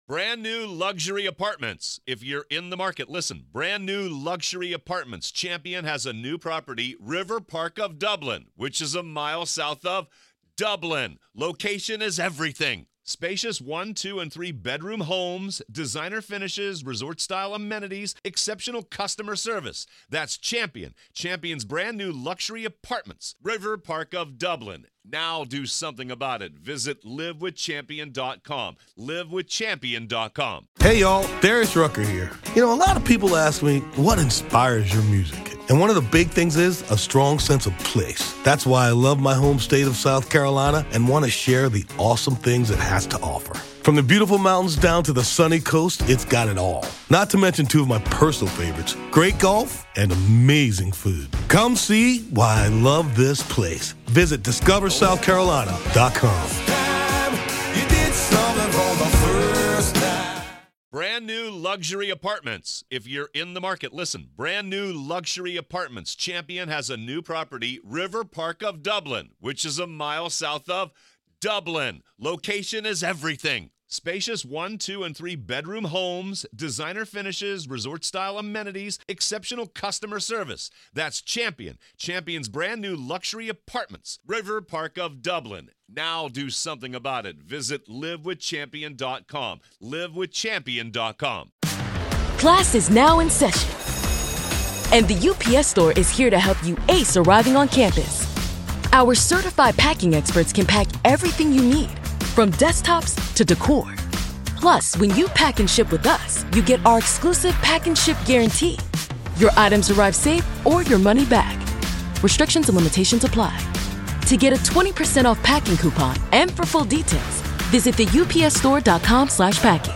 We give you the full testimony as it happened, without spin or commentary.